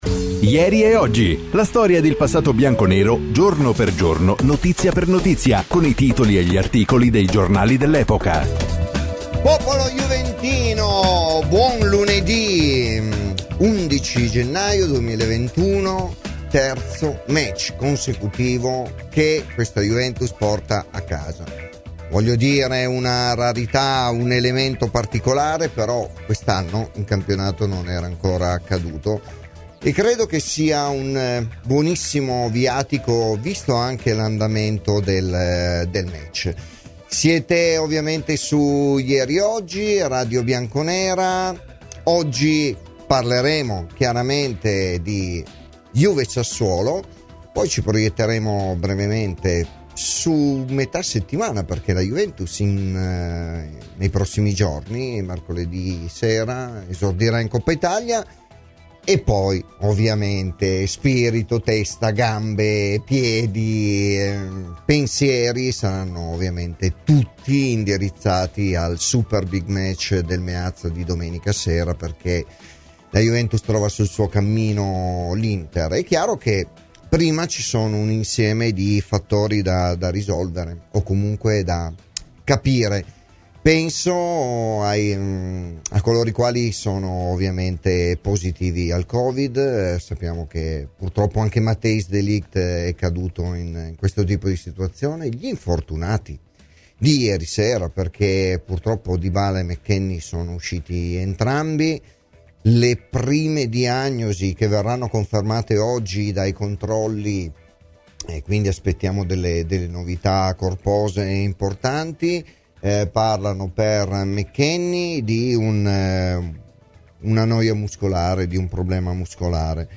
Intervistato da Radio Bianconera